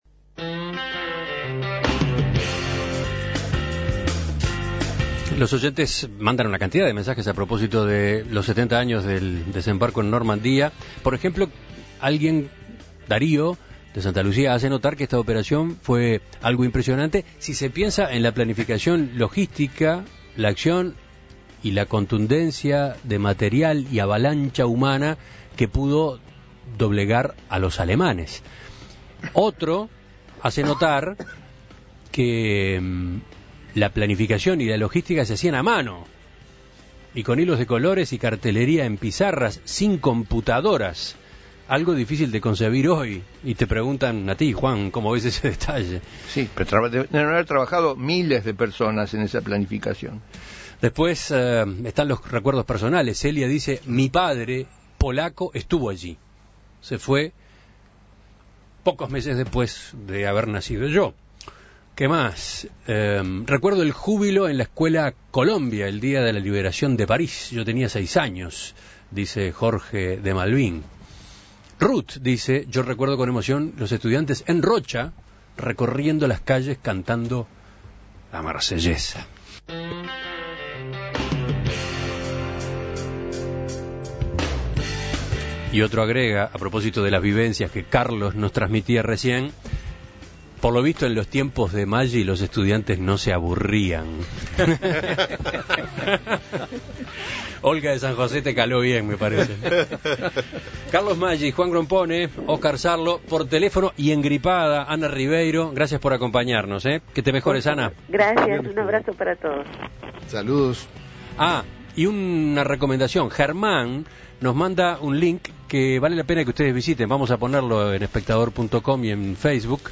por teléfono